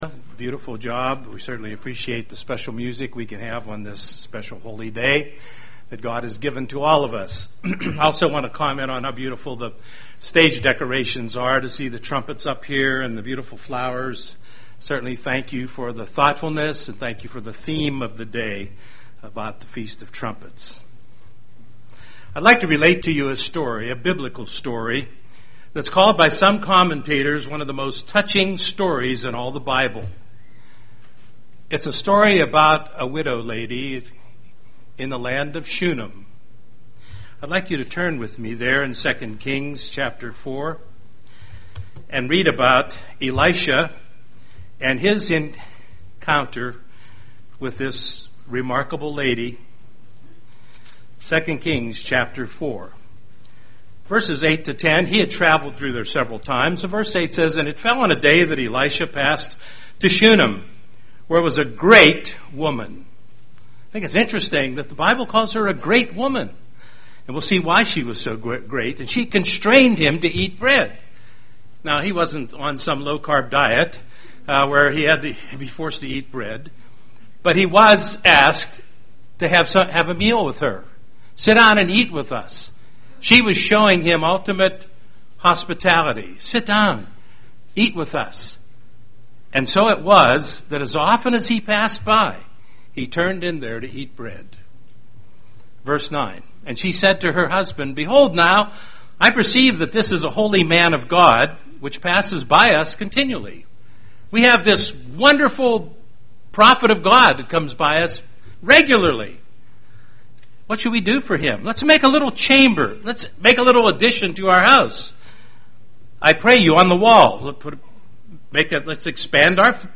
This message was given on the Feast of Trumpets.